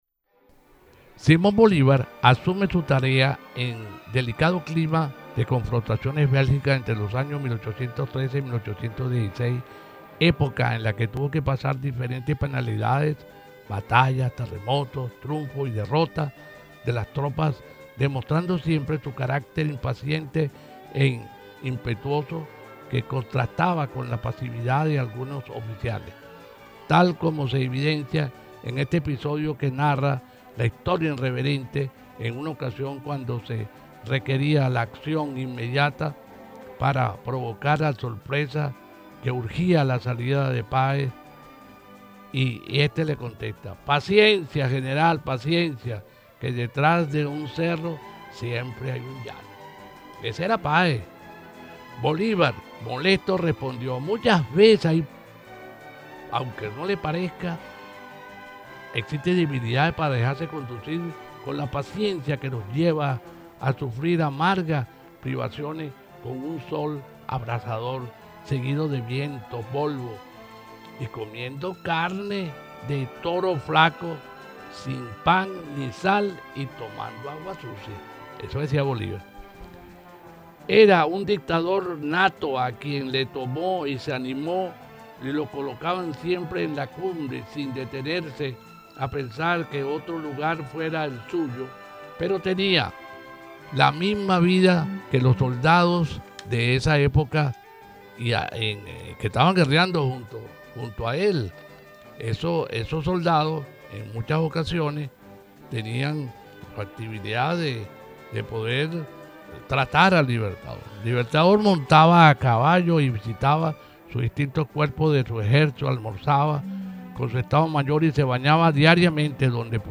con su estilo ameno y educativo